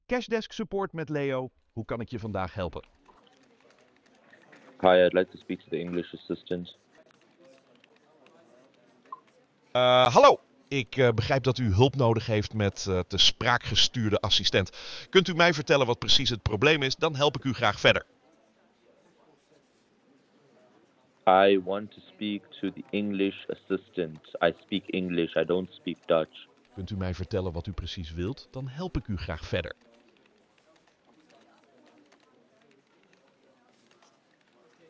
I'm clearly speaking English but it's not getting picked up